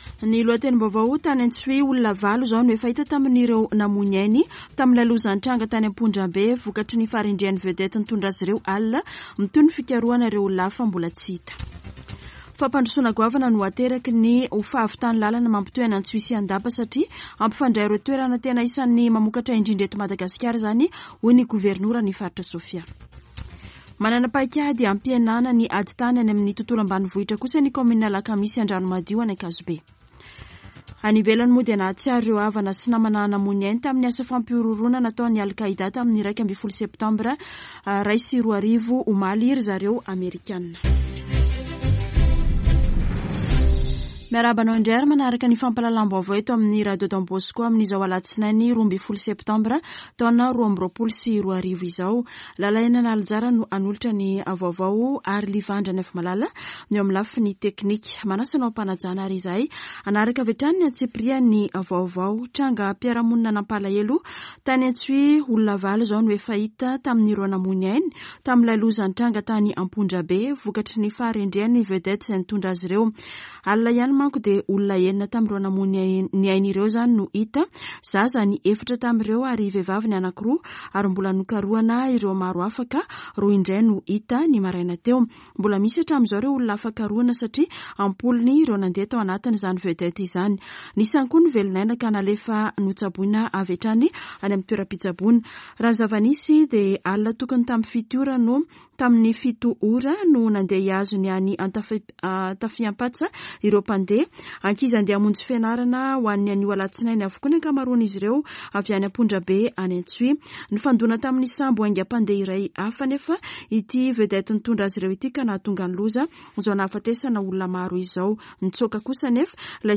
[Vaovao maraina] Alatsinainy 12 septambra 2022